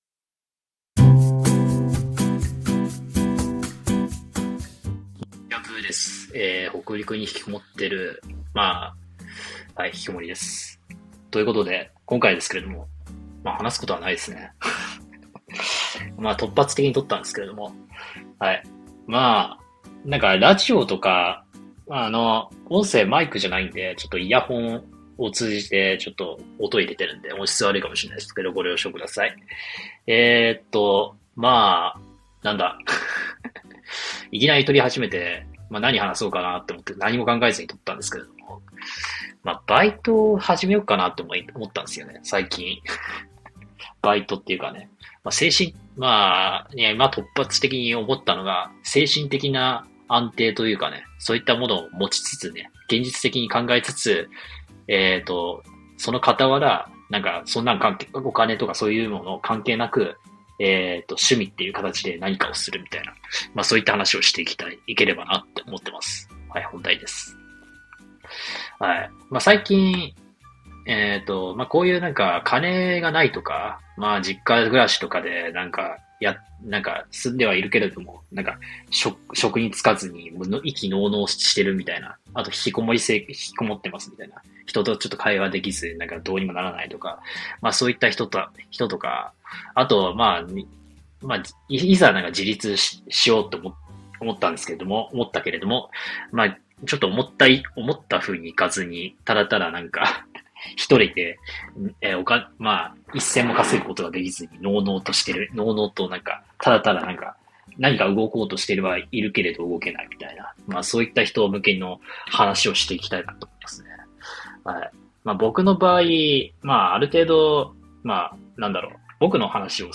【雑談】無職と漫画執筆で学んだこと&週一労働だけでもメンタル悪化防げると学んだ回